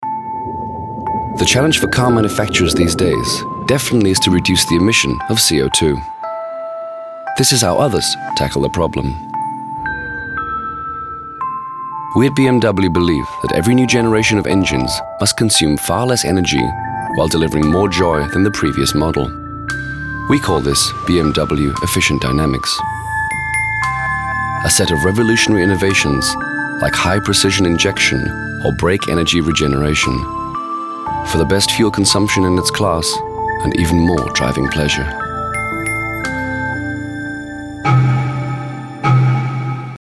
Middle Aged
My voice is friendly, trustworthy, and naturally conversational, making it well suited to brands and organisations looking to communicate clearly and authentically.